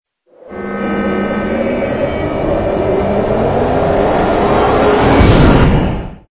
engine_ci_cruise_start.wav